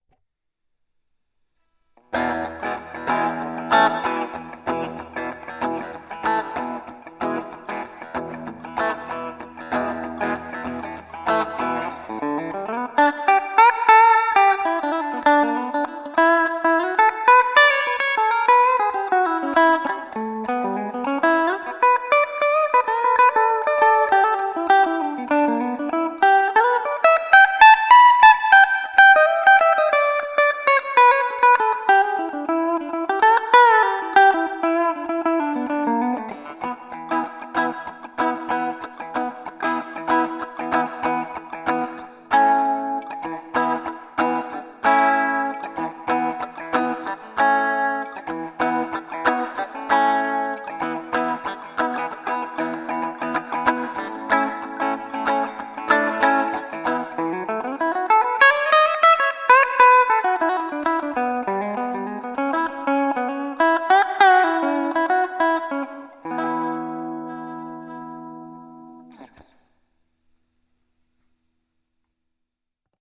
Samples: recorded with DR1 pocket recorder about 2 feet away